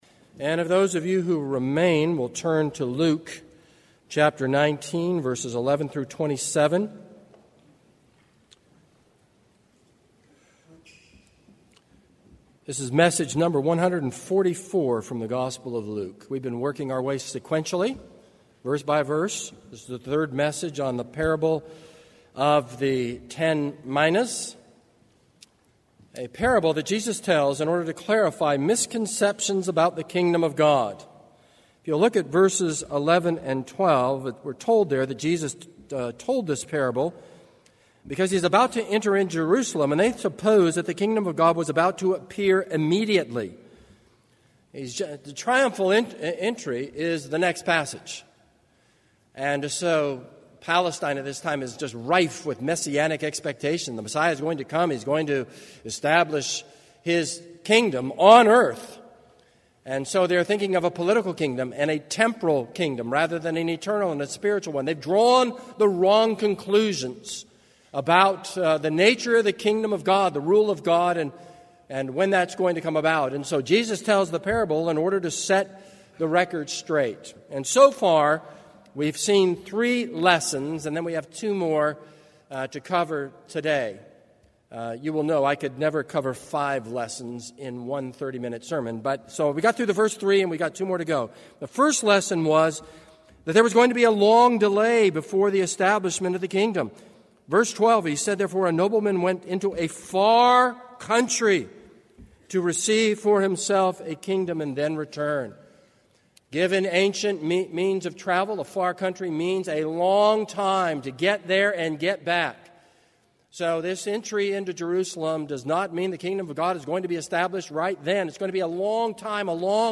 This is a sermon on Luke 19:11-27.